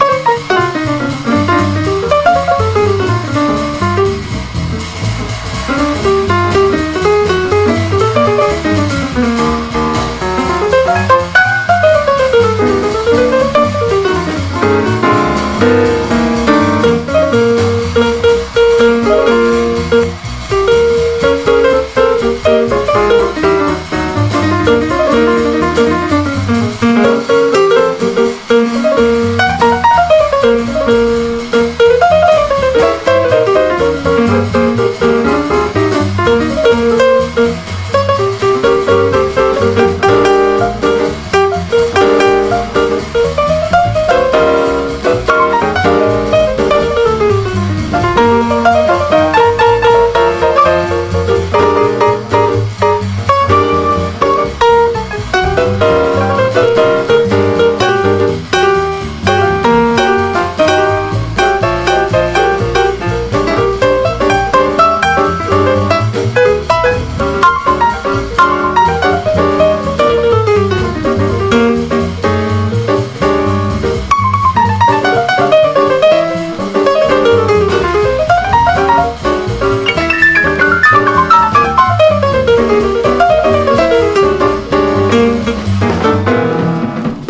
JAZZ / MAIN STREAM / PIANO
緊張感のあるピアノ・リフに心底惚れる
ピアノのリフがカッコイイのなんのって。